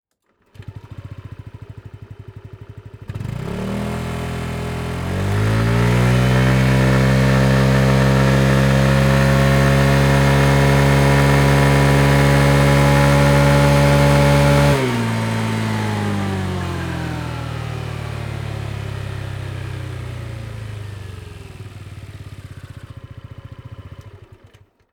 Ääninäytteet
Äänenlaatu: syvä ja elävä ääni.